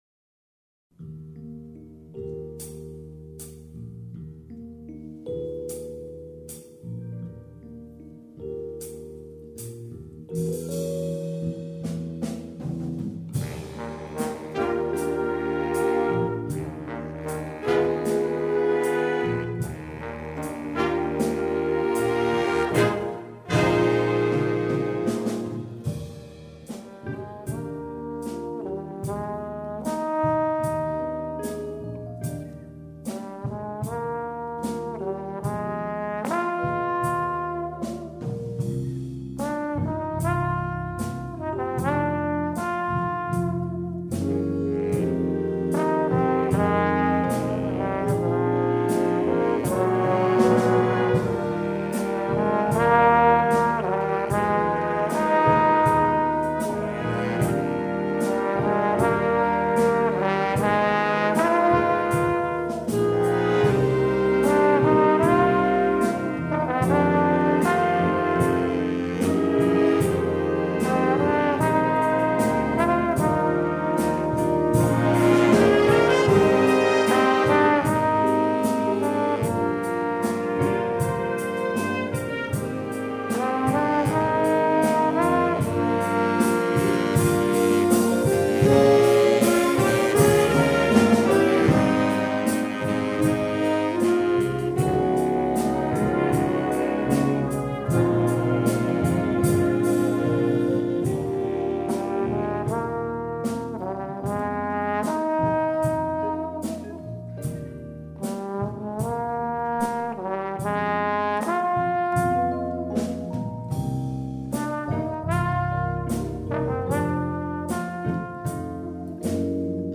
is a tender ballad featuring solo trombone